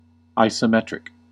Ääntäminen
IPA : /ˌaɪ.səʊˈmɛt.ɹɪk/